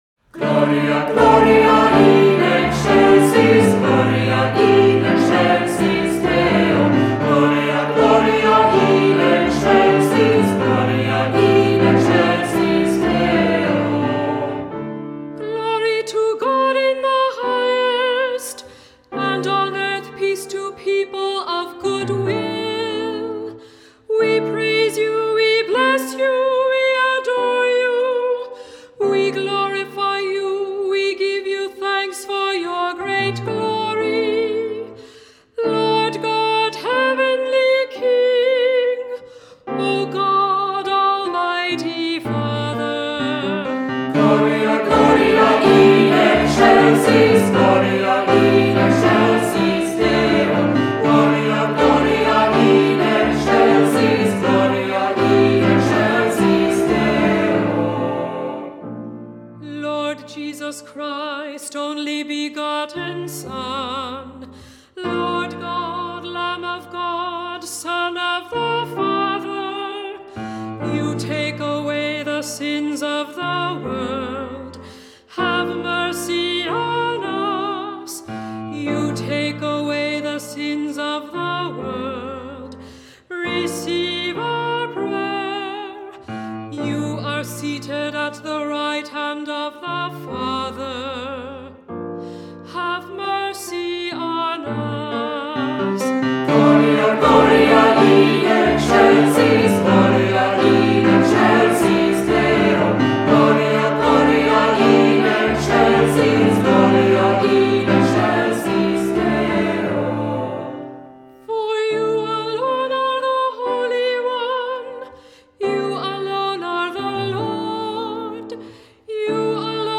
Voicing: SAB; Cantor; Descant